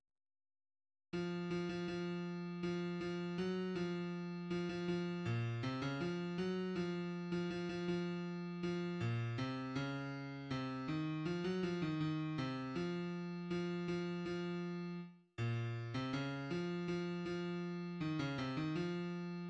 \clef bass \tempo 4=80 \key des \major \time 2/4